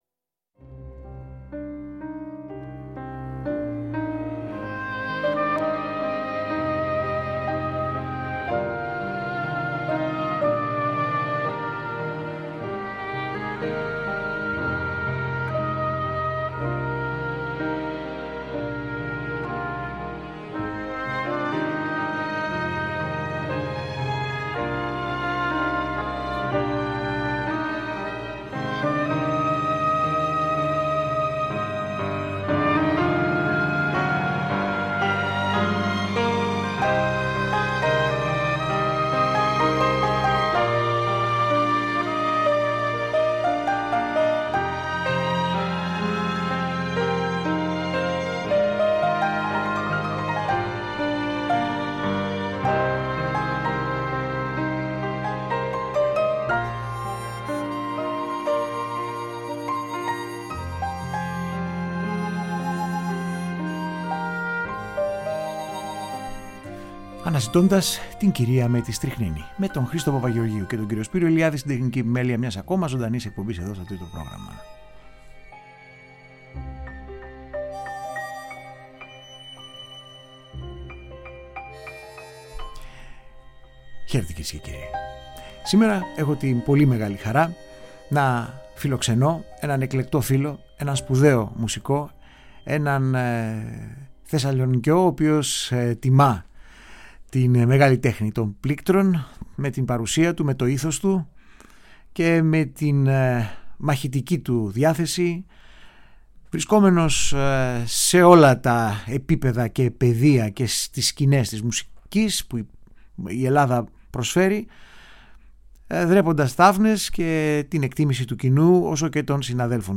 Αφιέρωμα – Συνέντευξη